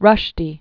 (rŭshdē), Salman Born 1947.